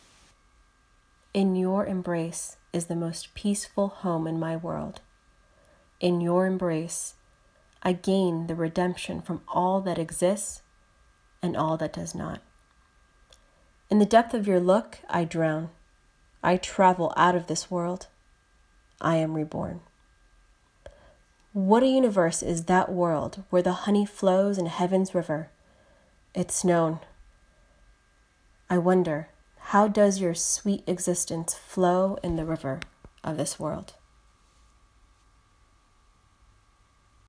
Narration by